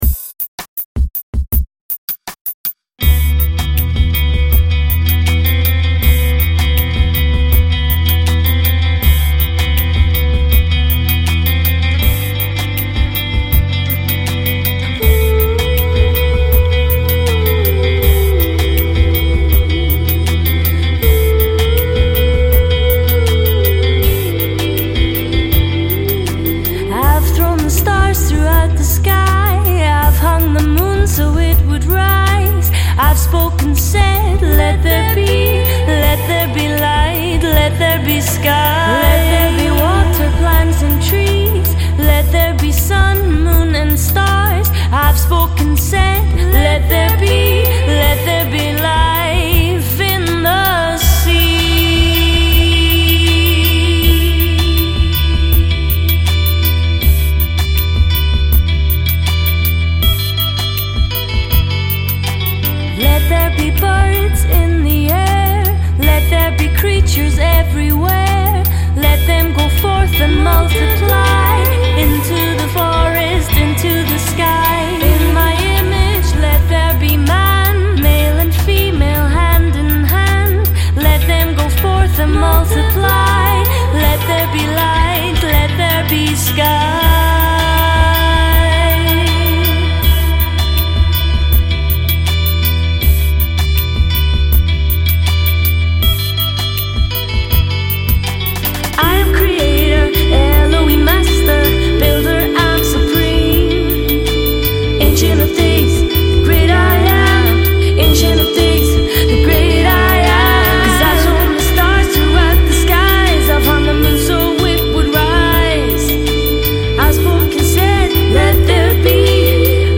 Lead & Background Vocals